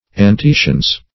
antecians - definition of antecians - synonyms, pronunciation, spelling from Free Dictionary Search Result for " antecians" : The Collaborative International Dictionary of English v.0.48: Antecians \An*te"cians\, n. pl.